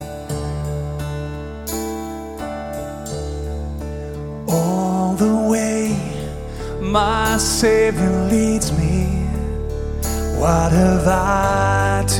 50 of the most popular modern worship songs
• Sachgebiet: Praise & Worship